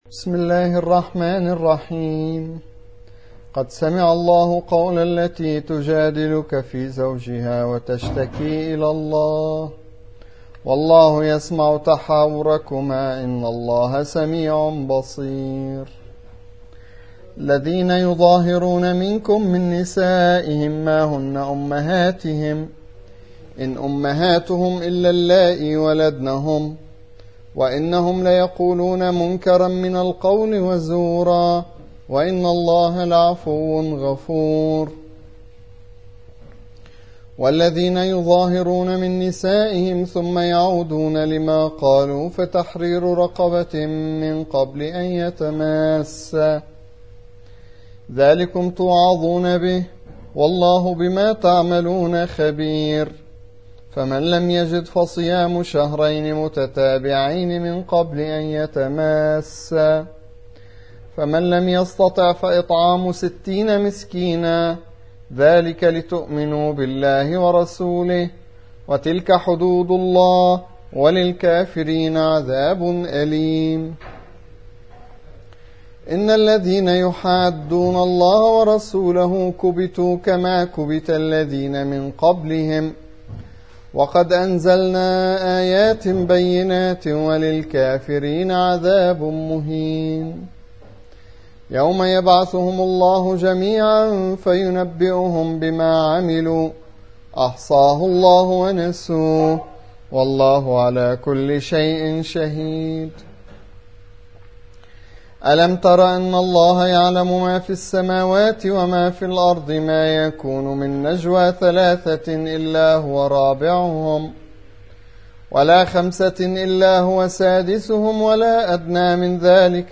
58. سورة المجادلة / القارئ